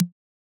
click-short.wav